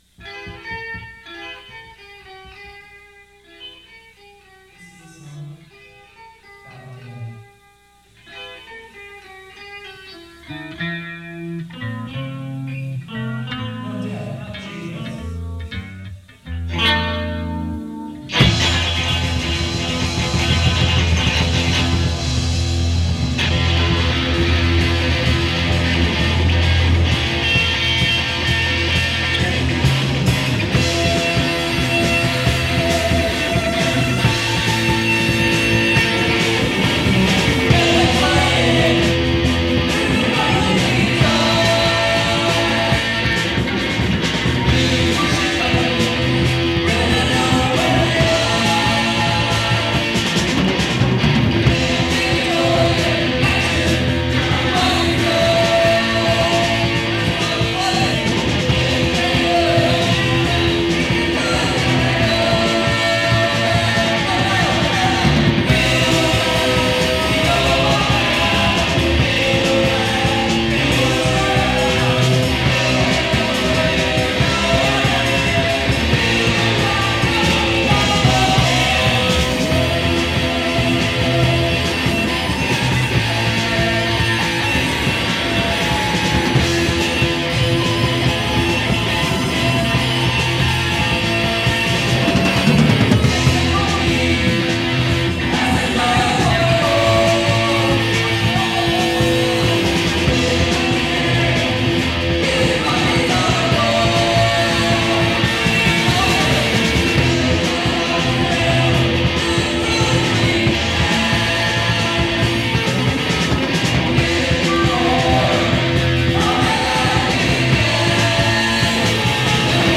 Tempo = picking up steam.
FieldoBlack_psychadelic.mp3